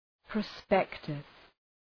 {prə’spektəs}
prospectus.mp3